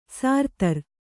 ♪ sārtar